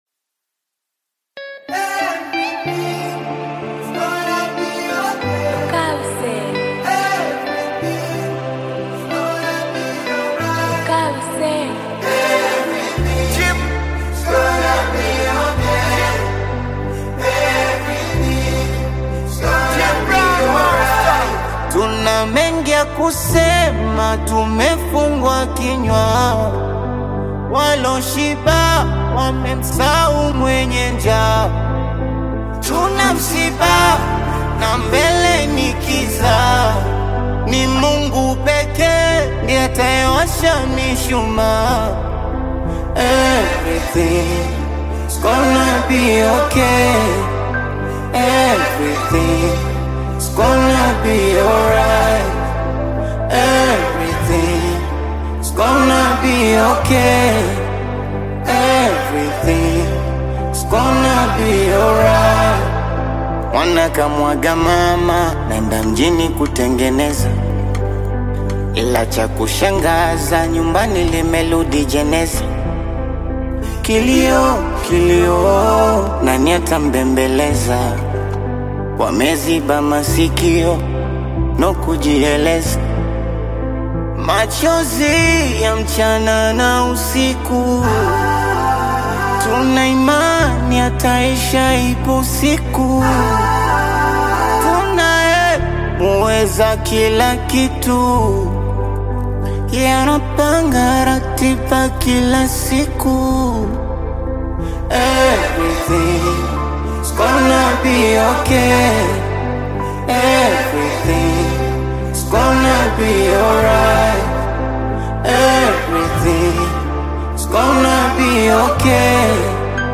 the Tanzanian vocalist and filmmaker